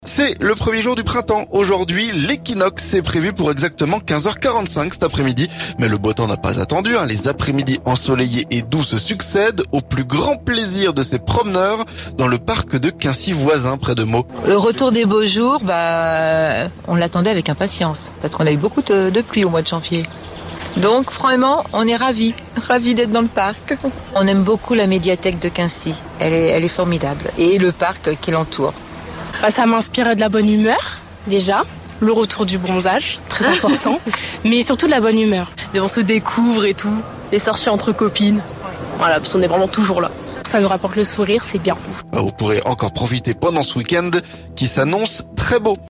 PRINTEMPS - Beau temps et douceur, notre reportage à Quincy-voisins
Les après-midi ensoleillés et doux se succèdent... Au plus grand plaisir de ces promeneurs dans le parc de Quincy-Voisins, près de Meaux.